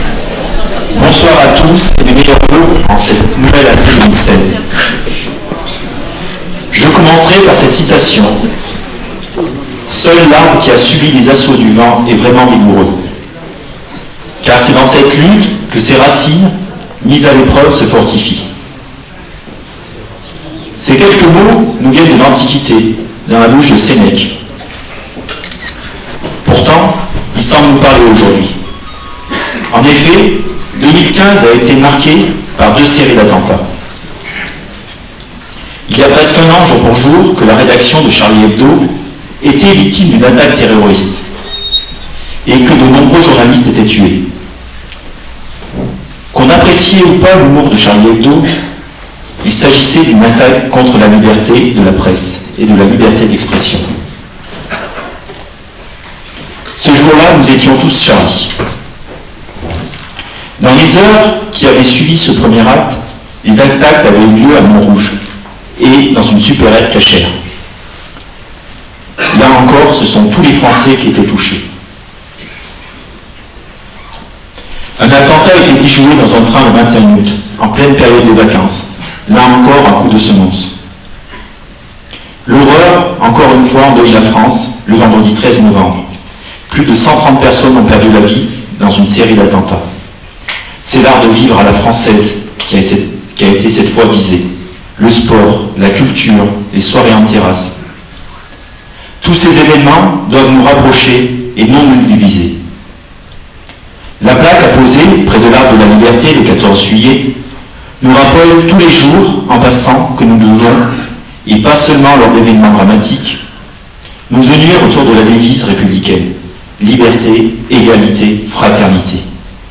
INTERVENTION DU MAIRE DE CRAPONNE LAURENT MIRMAND ET DES CONSEILLERS DEPARTEMENTAUX BERNARD BRIGNON ET MARIE AGNES PETI.
PLUS DE 400 PERSONNES ONT ASSISTE A CETTE SOIREE.